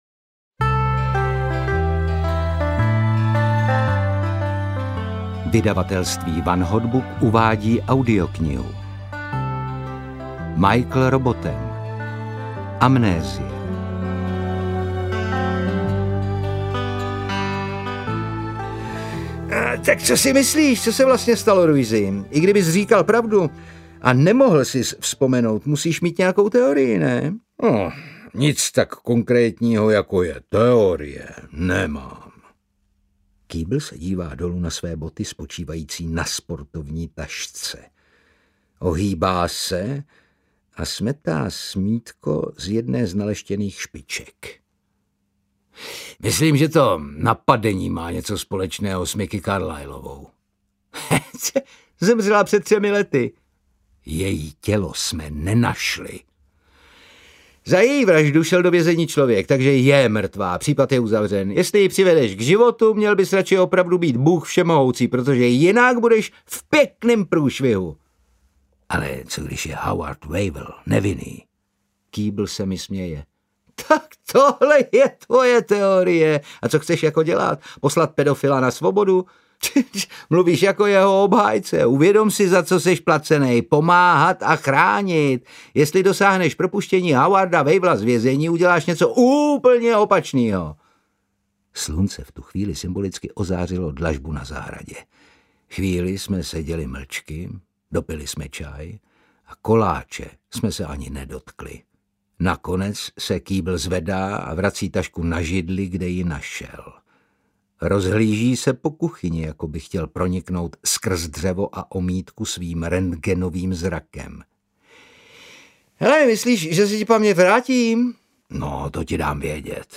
Amnézie audiokniha
Ukázka z knihy
• InterpretOtakar Brousek ml.